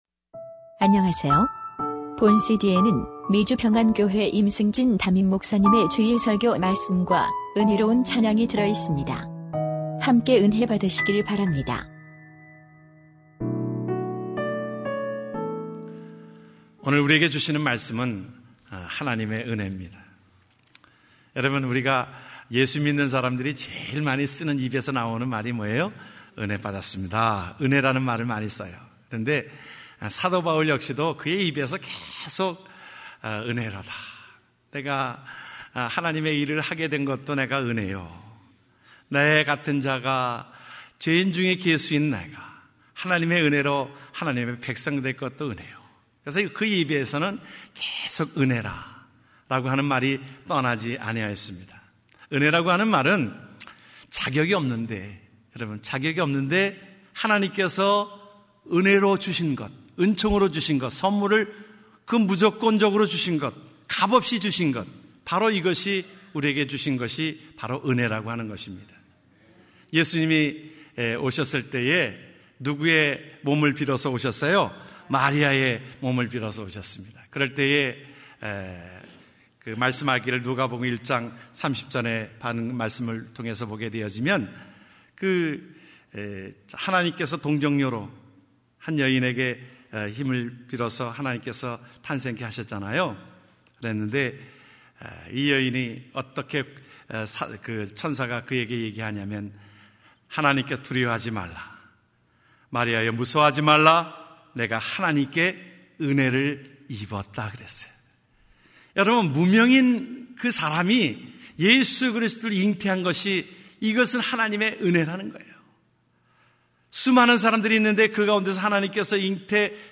2014년 12월 7일 미주평안교회 주일설교말씀 | 하나님의 은혜(고후 6:1-10)